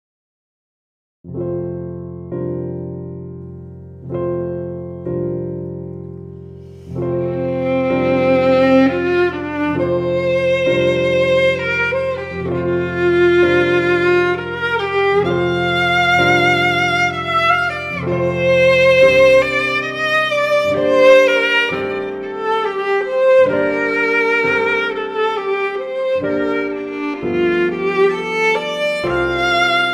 Voicing: Violin and Piano